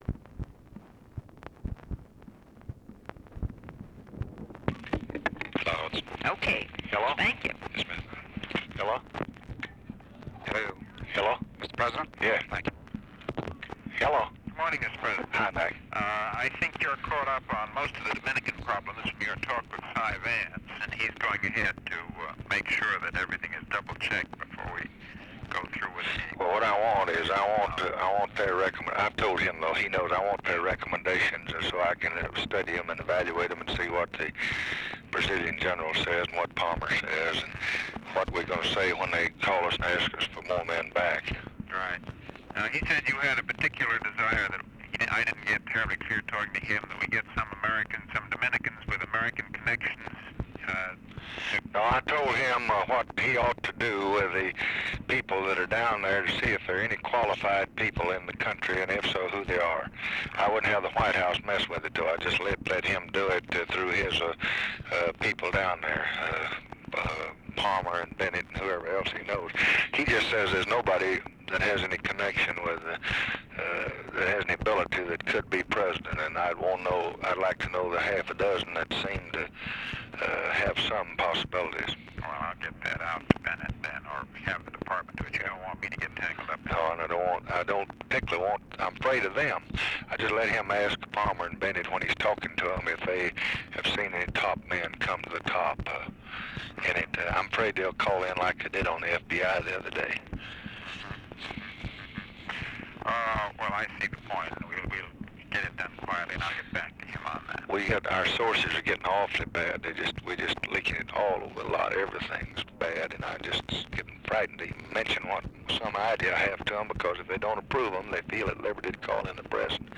Conversation with MCGEORGE BUNDY and UNIDENTIFIED MALE, May 31, 1965
Secret White House Tapes